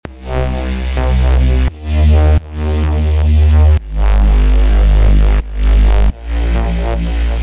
FUTURE BASS/EDM SERUM PRESETS
‘Neuro’ Bass
Neuro-Bass.mp3